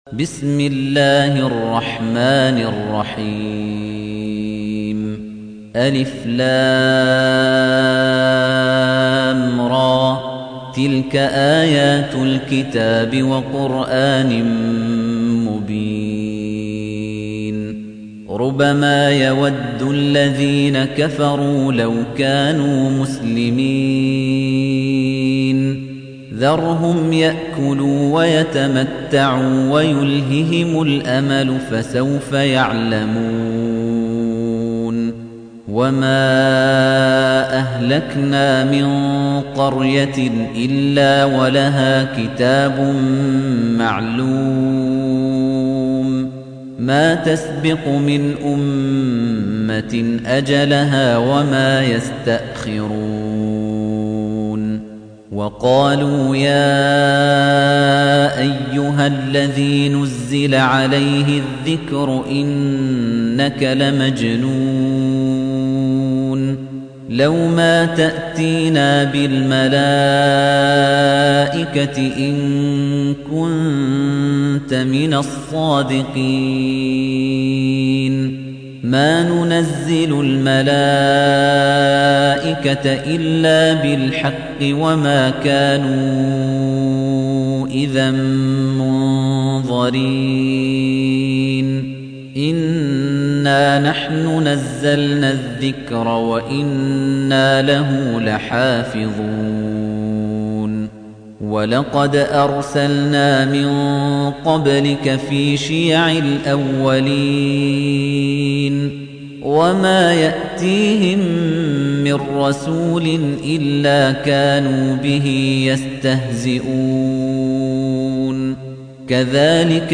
تحميل : 15. سورة الحجر / القارئ خليفة الطنيجي / القرآن الكريم / موقع يا حسين